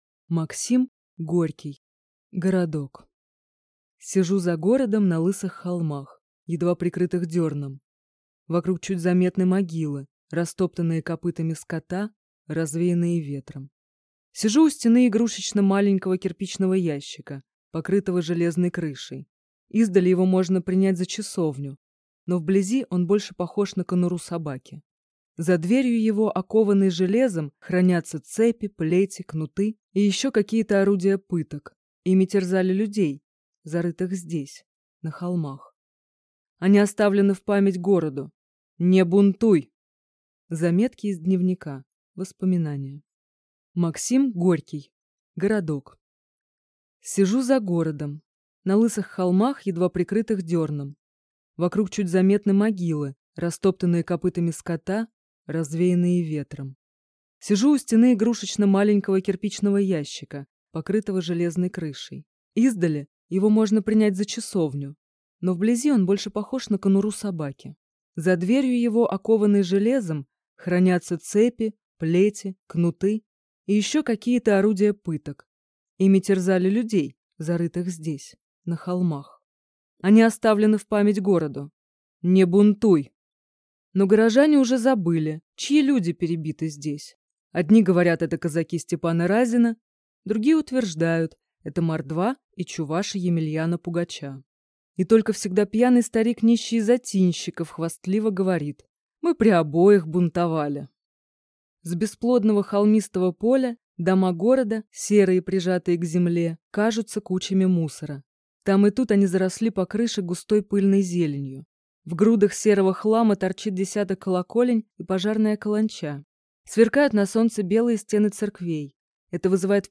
Аудиокнига Городок | Библиотека аудиокниг